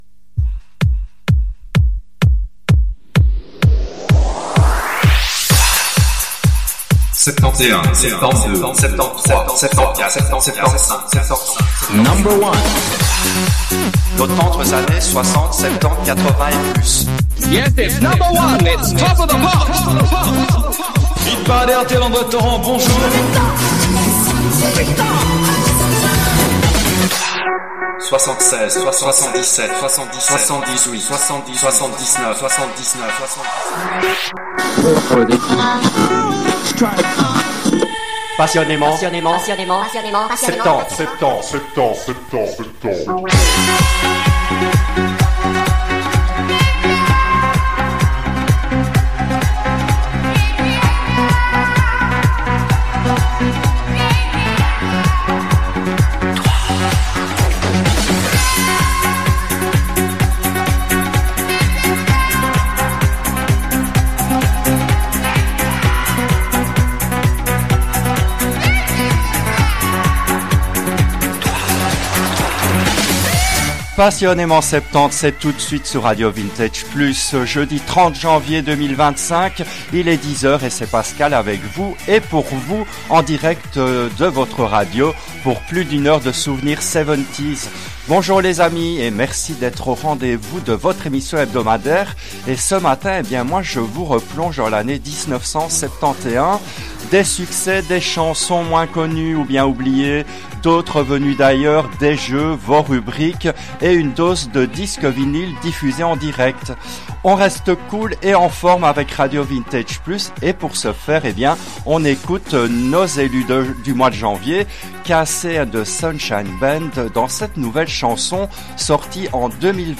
L’émission a été diffusée en direct le jeudi 30 janvier 2025 à 10h depuis les studios belges de RADIO RV+.